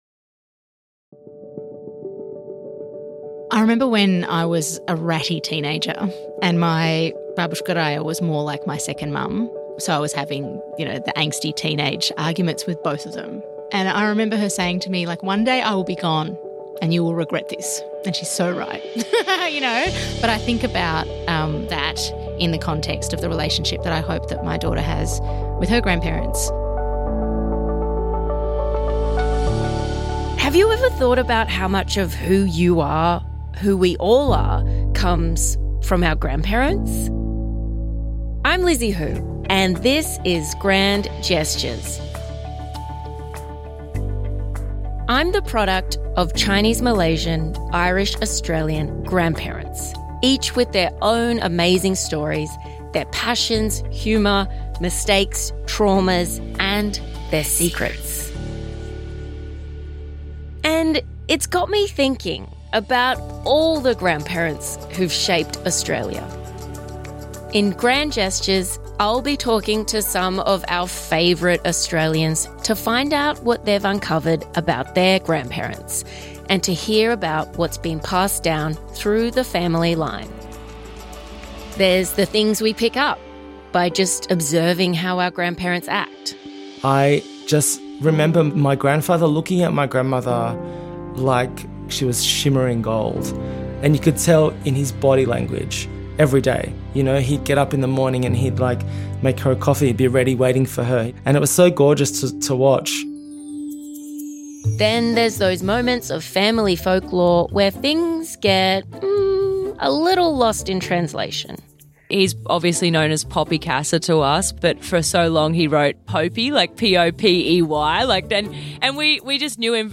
Comedian Lizzy Hoo dives into the memorable moments, family folklore and cultural traditions of your favourite Australians and their grandparents in this new podcast from SBS Audio.